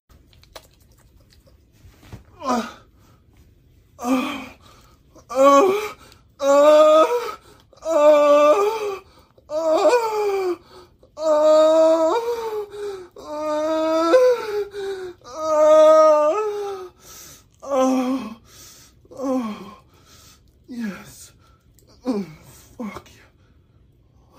Screaming